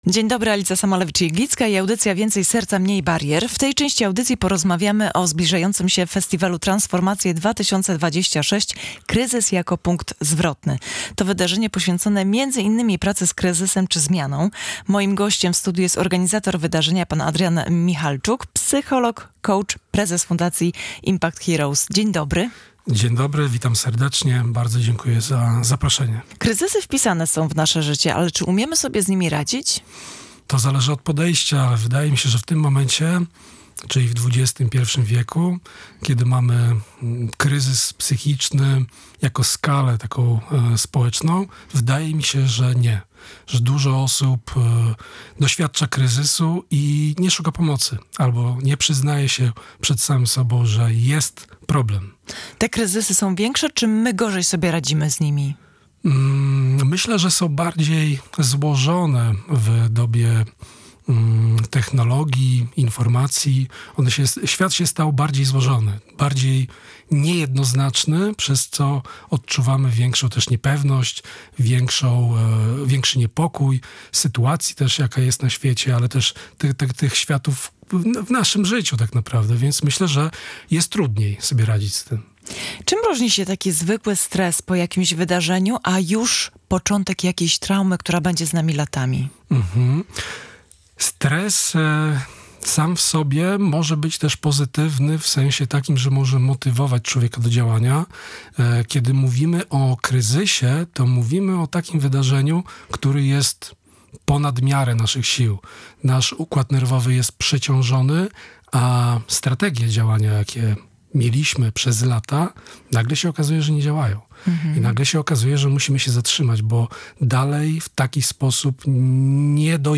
W drugiej części audycji rozmawialiśmy o charytatywnym wydarzeniu „Goń Zajączka”. To bieg, który łączy ruch, wiosenną energię i pomaganie.